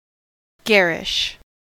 Ääntäminen
US : IPA : [ˈɡæɹ.ɪʃ]